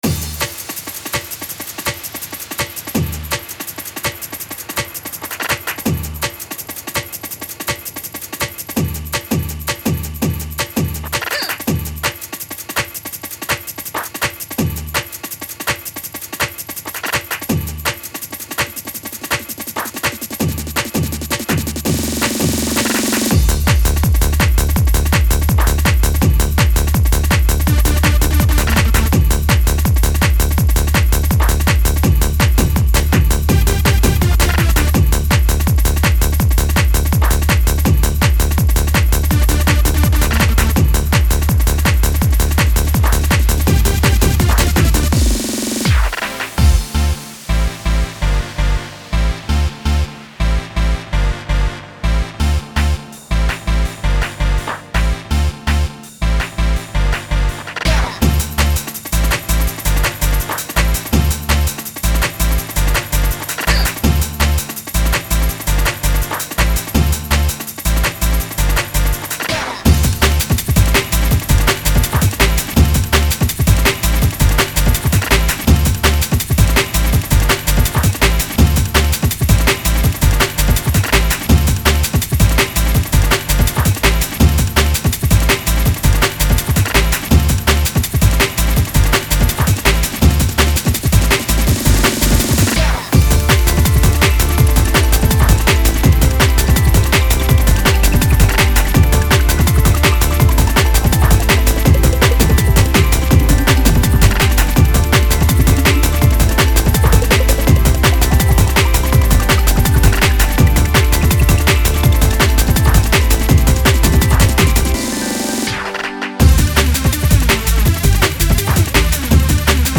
Music / 90s